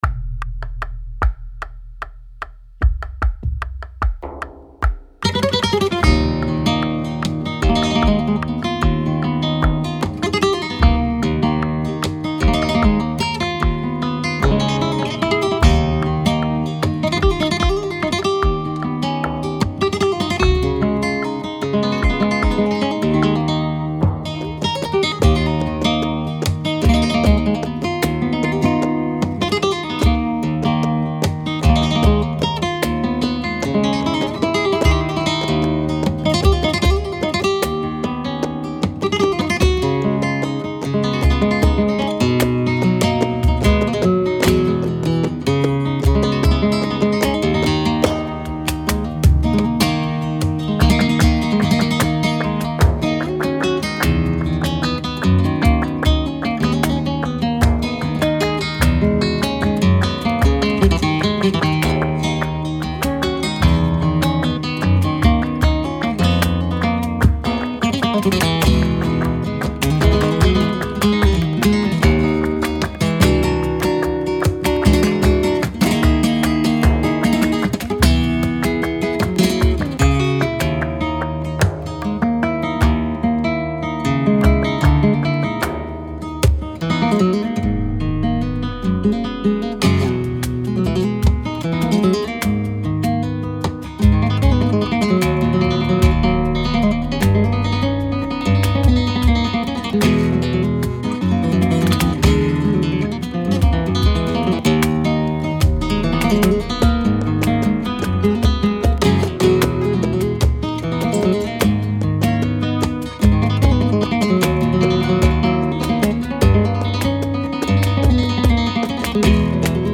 mode flamenco sur Ré
soleá por bulería
composition et guitare
percussions
palmas